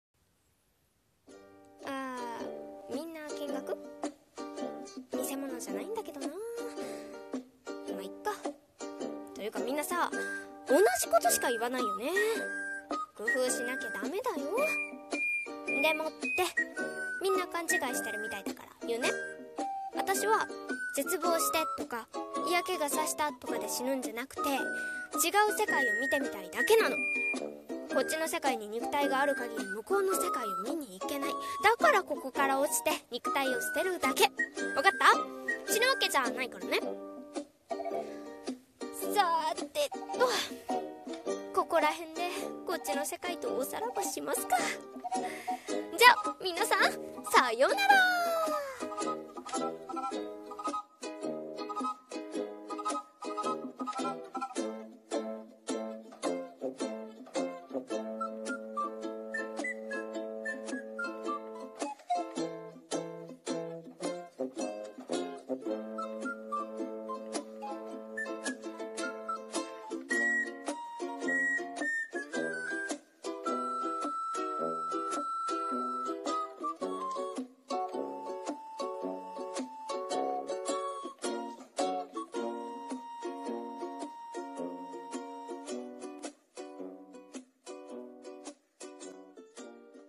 声劇台本一人用【勘違いしないでね】 声劇